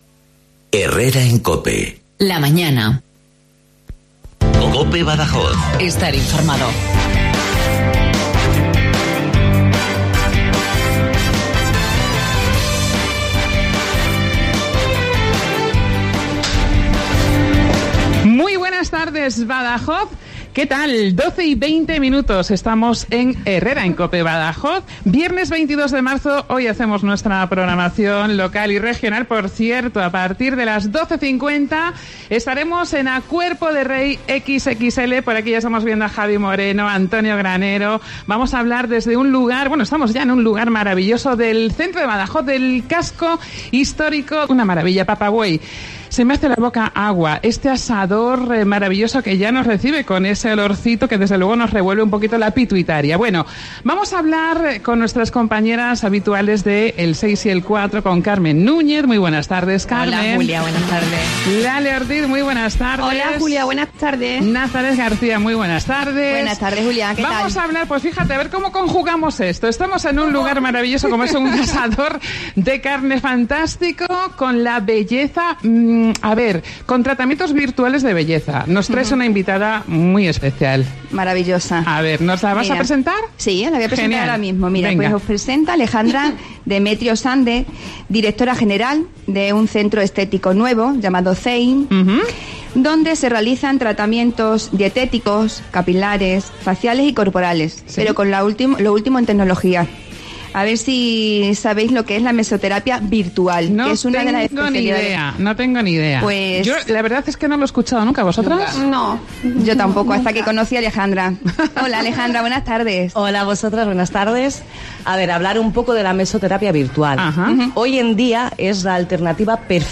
Hoy, el equipo de Herrera en COPE Badajoz ha dejado sus habituales estudios y se ha trasladado a un restaurante del casco antiguo donde se come la mejor carne de vacuno.